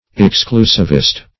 Search Result for " exclusivist" : The Collaborative International Dictionary of English v.0.48: Exclusivist \Ex*clu"siv*ist\, n. One who favor or practices any from of exclusiveness or exclusivism.